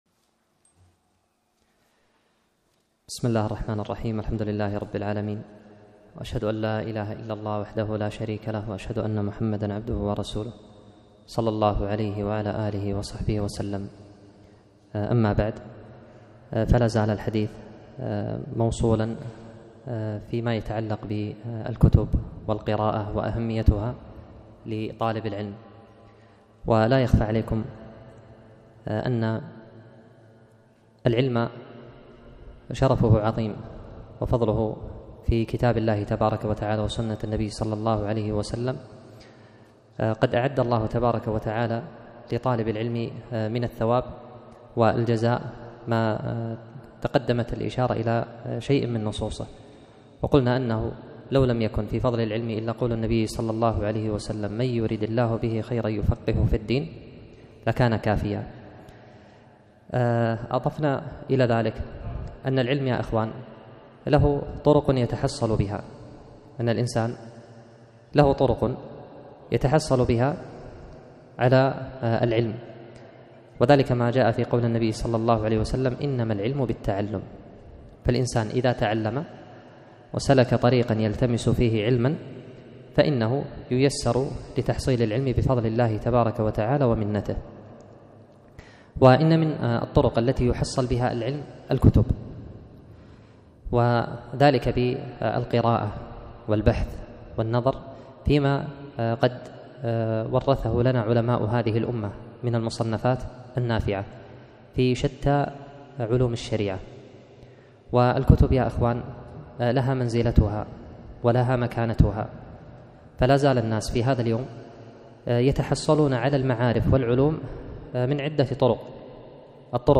محاضرة - اقتناء الكتب توجيهات وإرشادات